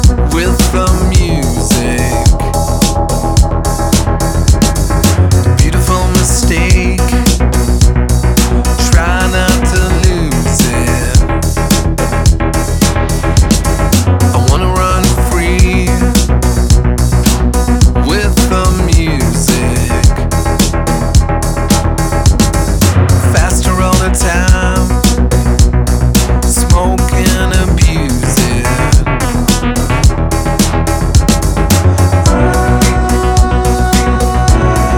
Жанр: Альтернатива Длительность